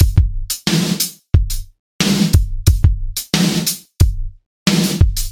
Whoo Drum
标签： 90 bpm Hip Hop Loops Drum Loops 918.79 KB wav Key : Unknown
声道立体声